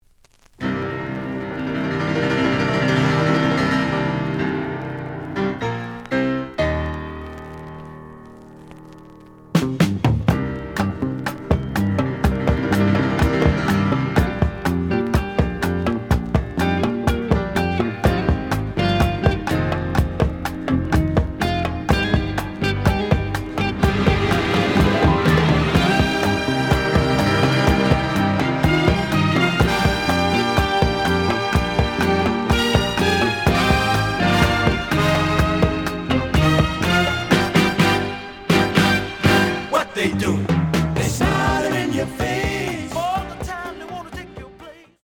The audio sample is recorded from the actual item.
●Genre: Soul, 70's Soul
●Record Grading: VG~VG+ (傷はあるが、プレイはおおむね良好。Plays good.)